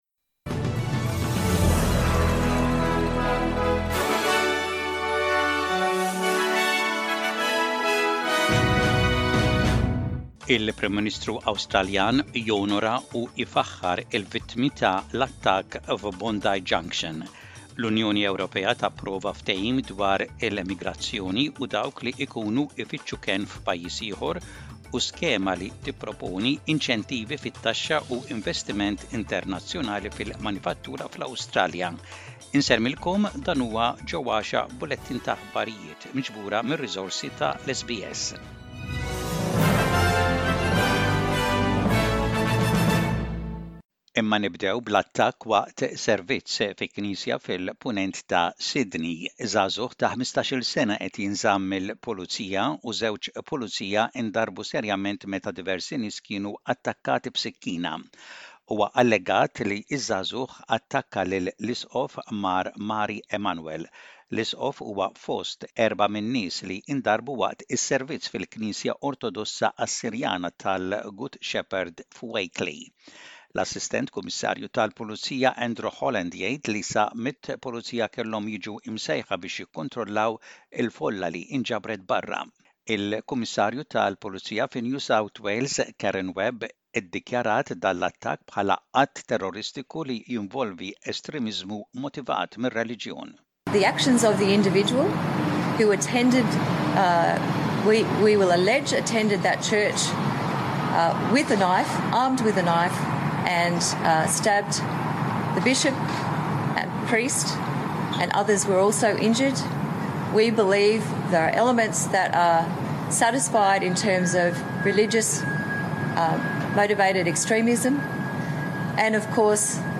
SBS Radio | Maltese News: 16.04.2024